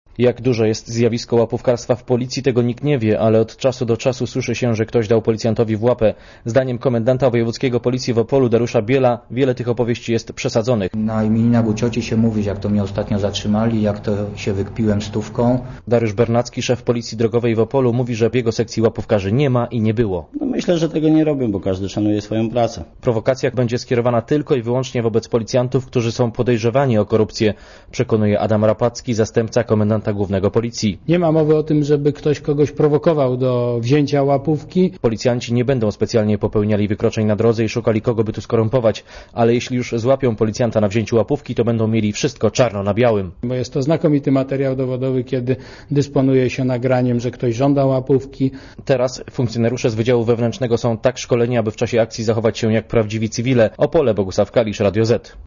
Posłuchaj relacji korespondenta